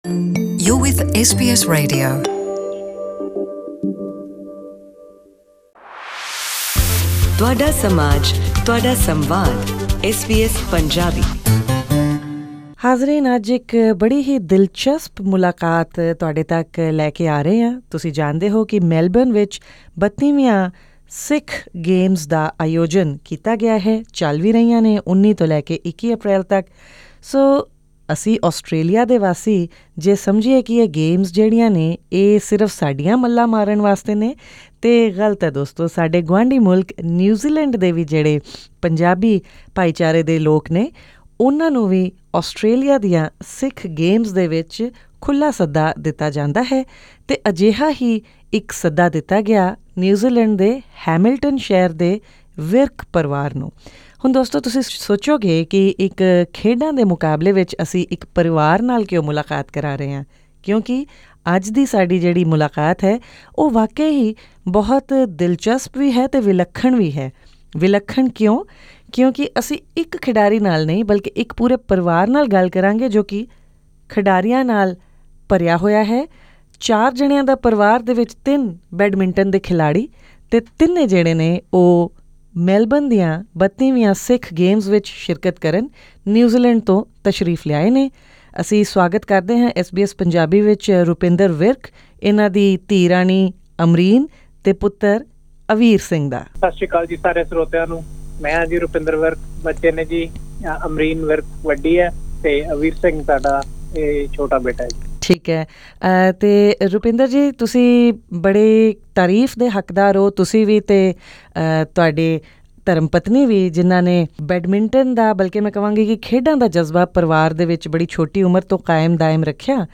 Listen to this interview in Punjabi by clicking on the player at the top of the page.